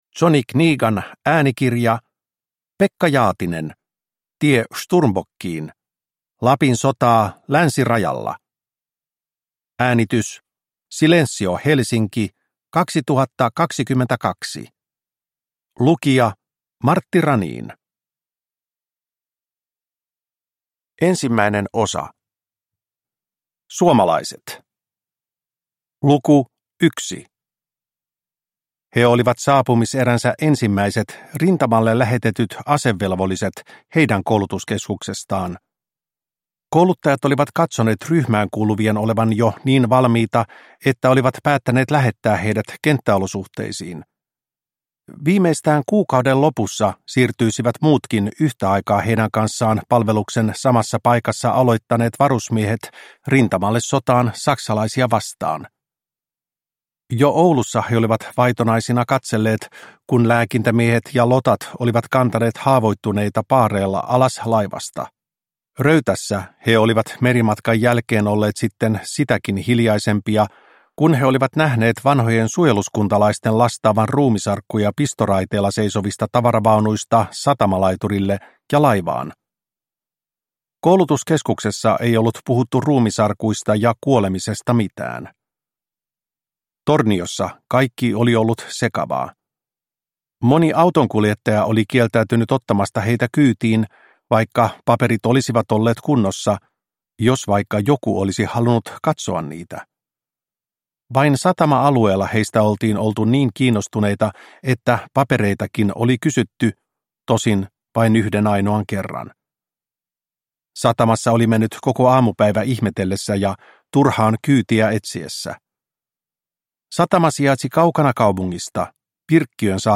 Tie Sturmbockiin – Ljudbok